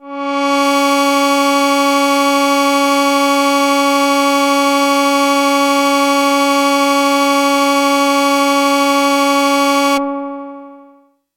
标签： B4 MIDI音符-71 Korg的单 - 聚 合成器 单票据 多重采样
声道立体声